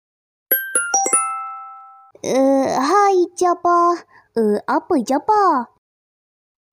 Genre: Nada dering imut